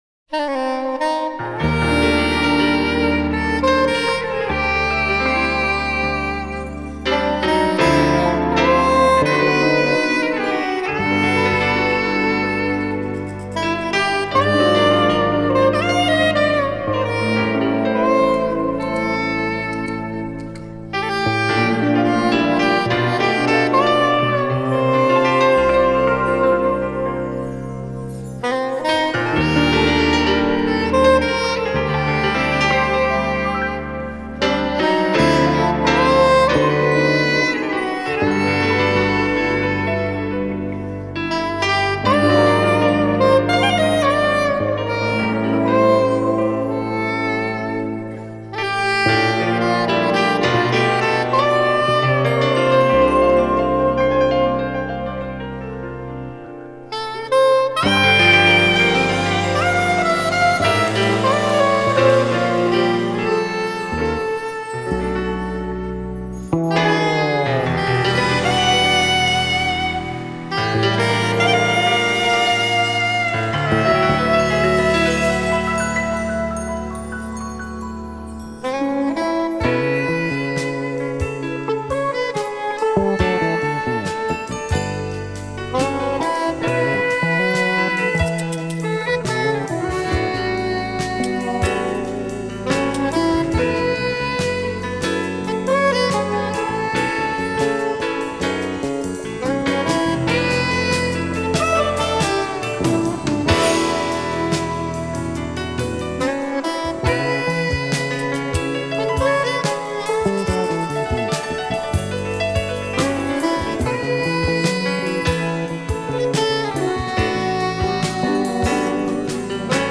Keyboards, Vocals
Saxophon
Guitar
Electric Bass
Drums, Percussion